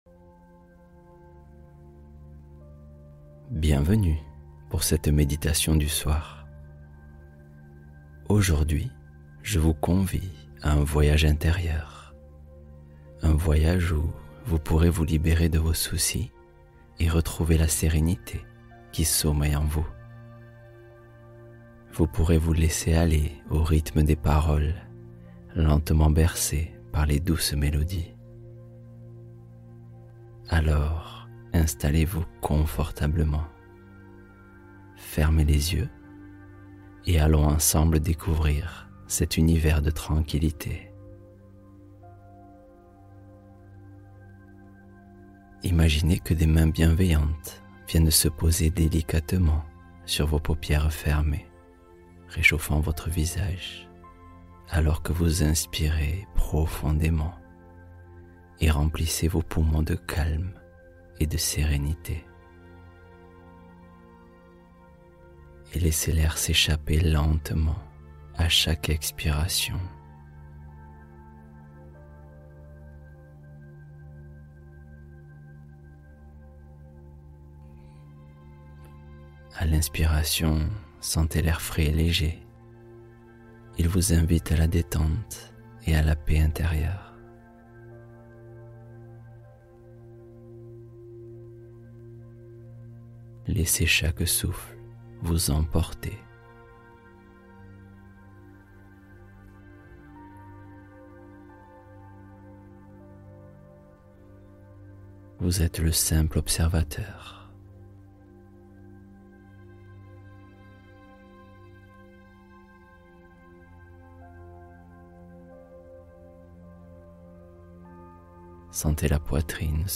Sommeil Profond : Histoire hypnotique pour un repos sans effort